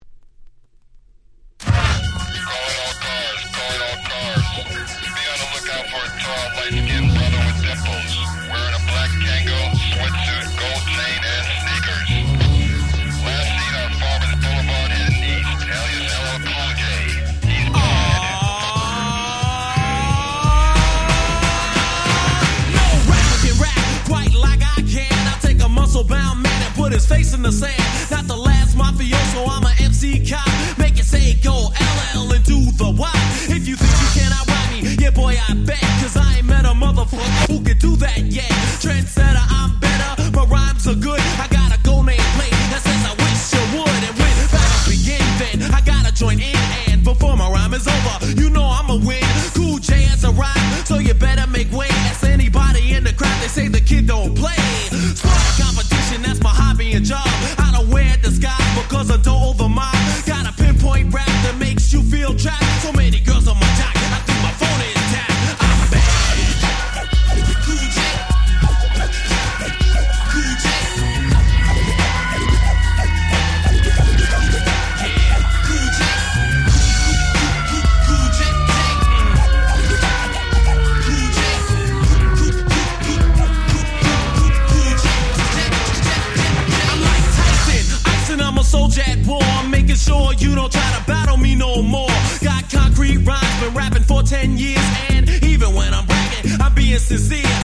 88' Super Hit Hip Hop !!
問答無用の80's Hip Hop Classics！！
これぞまさに「ヒップホップバラード」でしょう！！